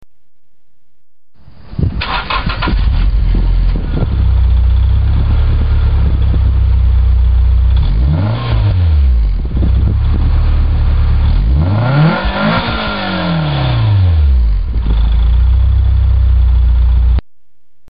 若干音質が劣化してますがご勘弁を（；−−）ﾉ
レベル１が静かな方で、逆にレベル５が爆音です。
登録No． パーツの種類 マフラー
音量 レベル２
・インナーサイレンサー無し。